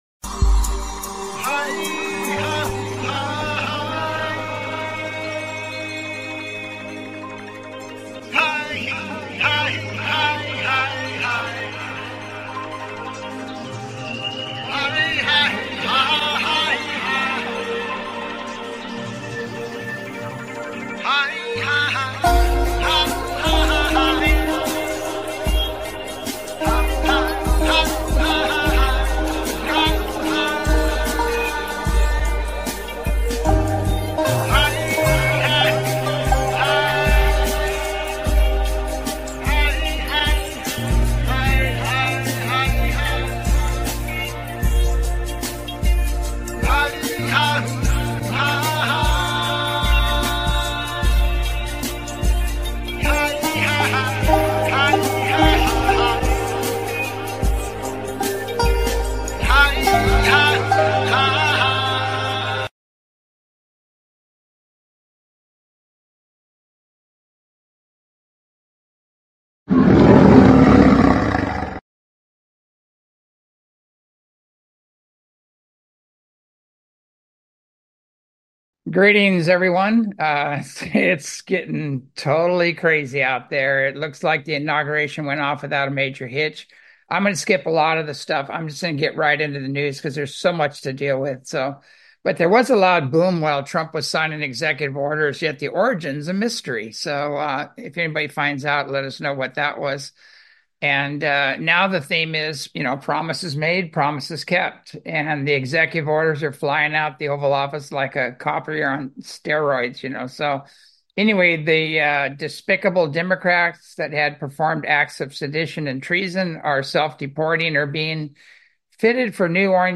Talk Show Episode, Audio Podcast, As You Wish Talk Radio and Inaguration Gitmo 3, Pardons Legal, Asended Masters, UFOs And Drones on , show guests , about Inaguration Gitmo 3,Pardons Legal,Asended Masters,UFOs And Drones, categorized as Earth & Space,News,Paranormal,UFOs,Philosophy,Politics & Government,Science,Spiritual,Theory & Conspiracy
As you Wish Talk Radio, cutting edge authors, healers & scientists broadcasted Live from the ECETI ranch, an internationally known UFO & Paranormal hot spot.